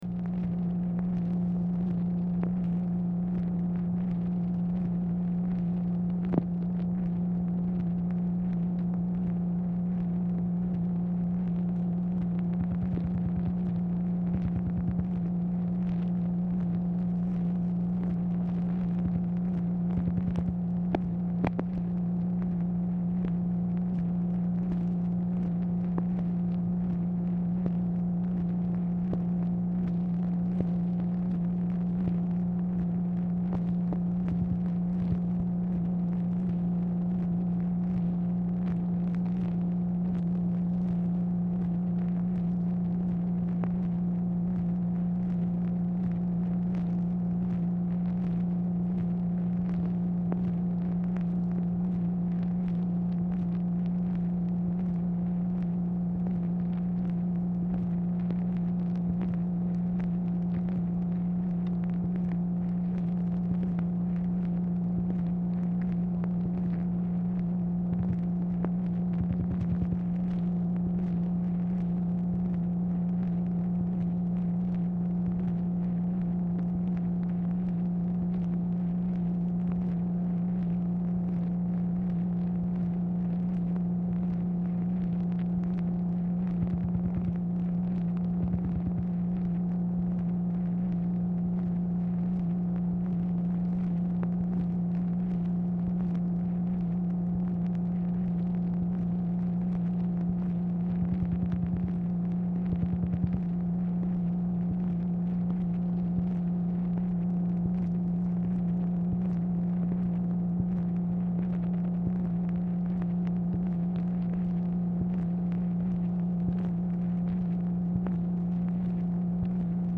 Telephone conversation # 9496, sound recording, MACHINE NOISE, 1/14/1966, time unknown | Discover LBJ